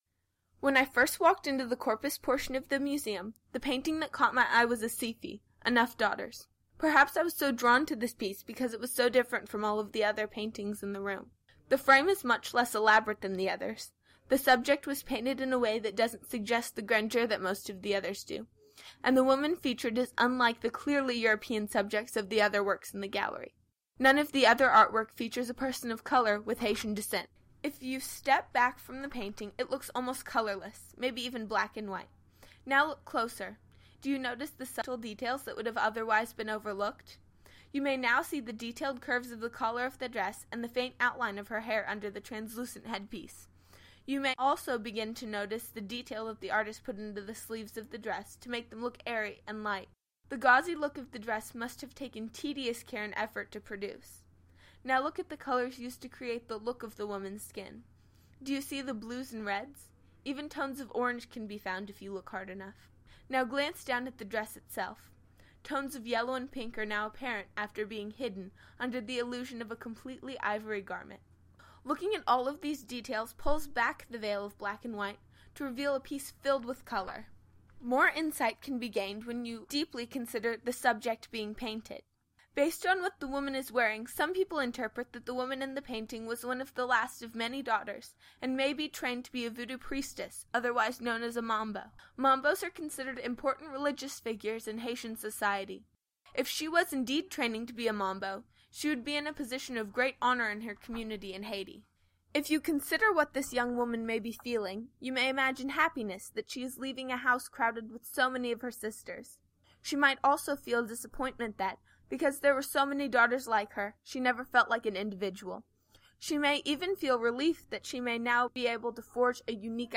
Audio Tour – Bulldog Podcast